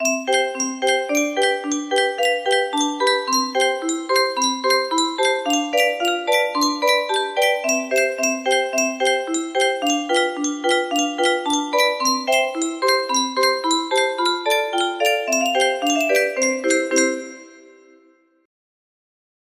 Clone of Ragtime Tune music box melody
Wow! It seems like this melody can be played offline on a 15 note paper strip music box!